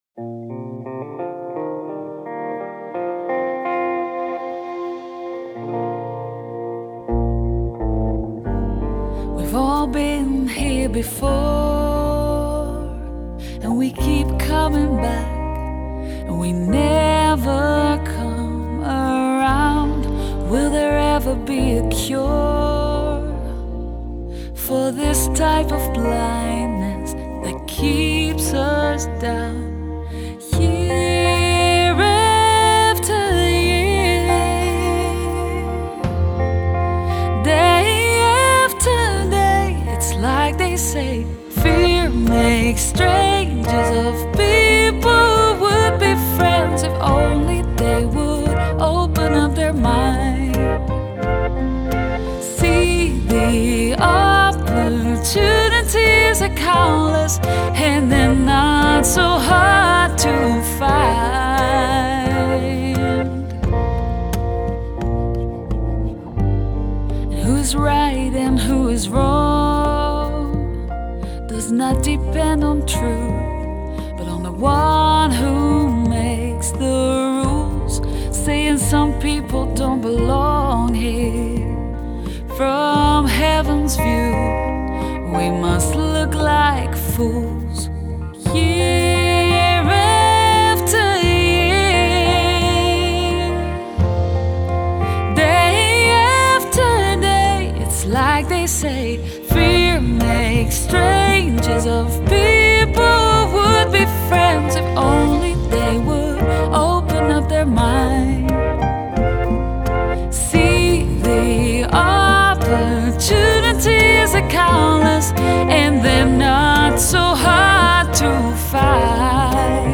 Genre: Pop, Folk Pop, Jazzy Pop